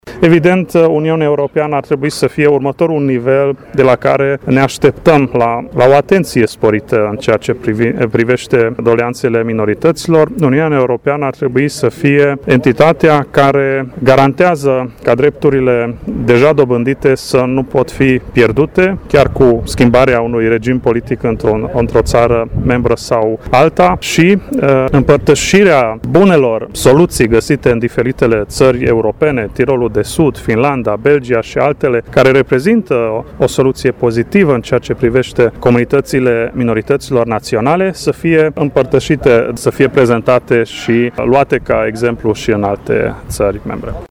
Preşedintele Federaţiei Minorităţilor Naţionale din Europa (FUEN), Vincze Loránt, a declarat că iniţiatorii proiectului şi-au propus să adune, în decurs de un an de zile, un milion de semnături din mai multe state europene, dintre care 250.000 de semnături din România, pentru susţinerea drepturilor minorităţilor: